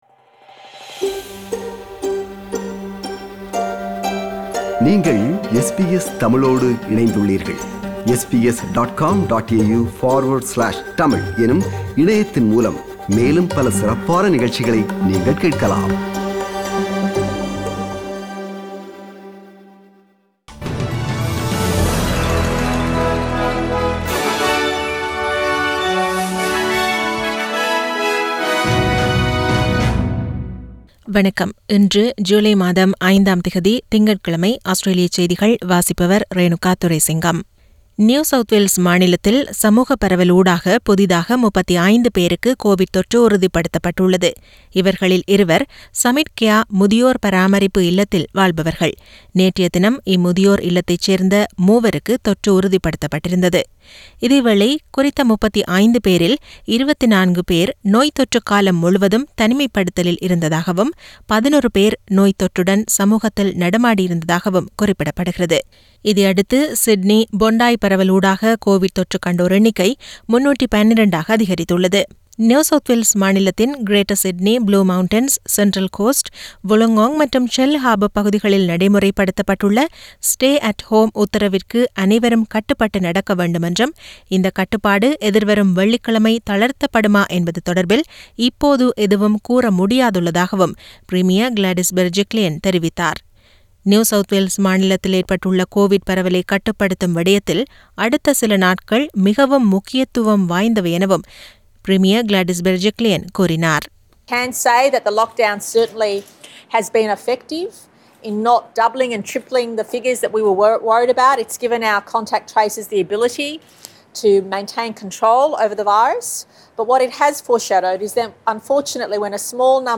Australian news bulletin for Monday 05 July 2021.